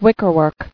[wick·er·work]